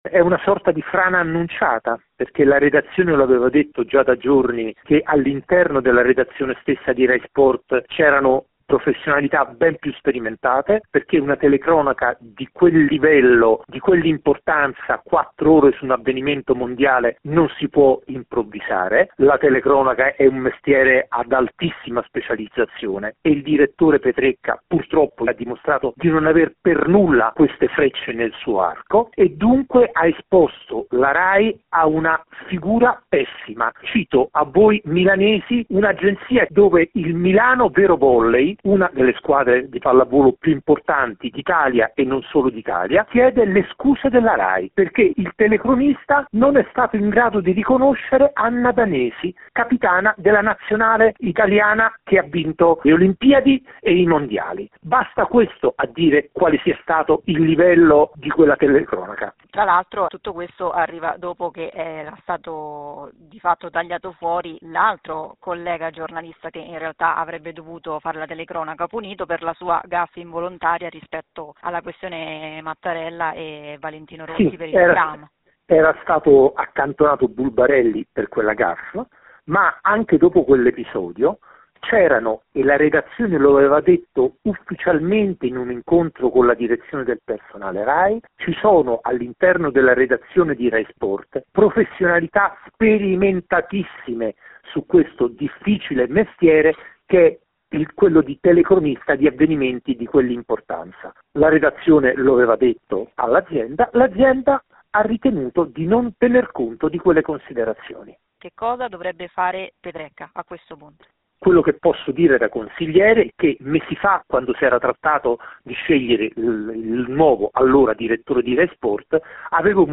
L’intervista